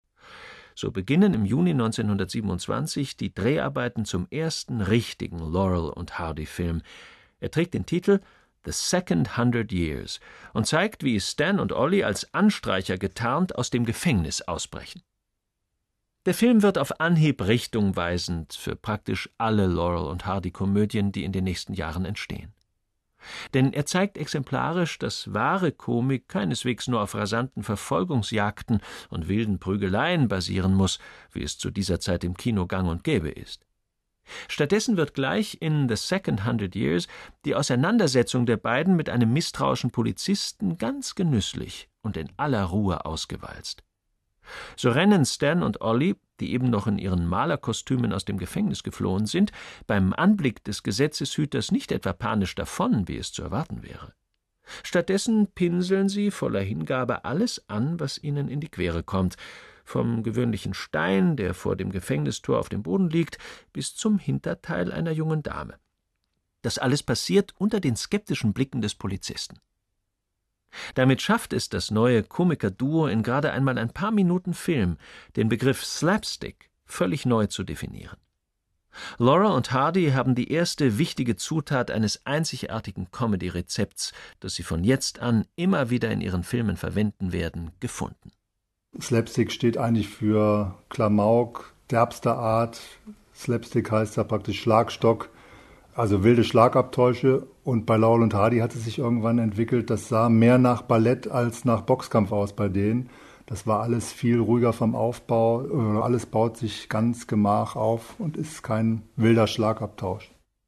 Audio-CDs • Hörbuch • Hörbuch; Feature • Hörbuch